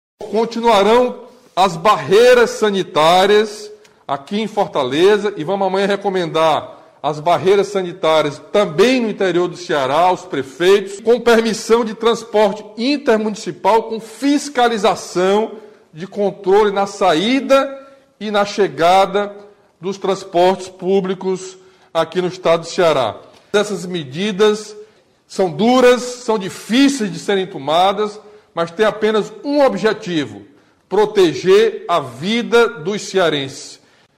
O governador Camilo Santana fala sobre as medidas decretadas para buscar a redução da contaminação da Covid-19, válidas em todo o Estado.